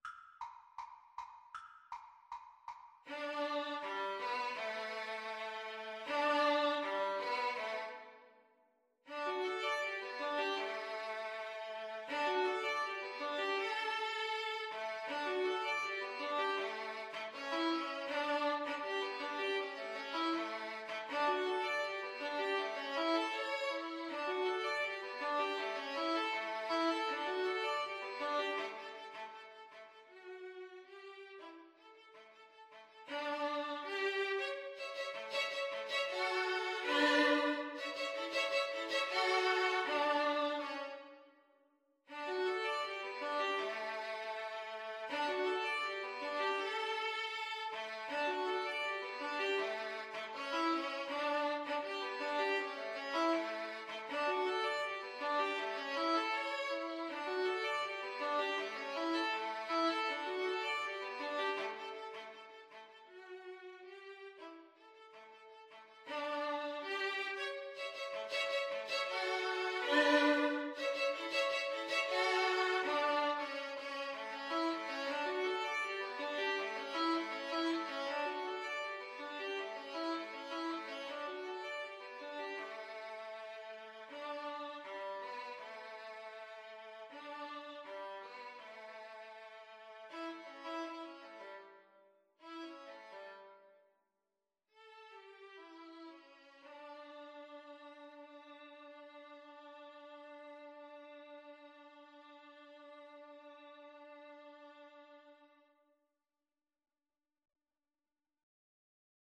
Free Sheet music for Violin Duet
4/4 (View more 4/4 Music)
D major (Sounding Pitch) (View more D major Music for Violin Duet )
Fast and bright = c. 160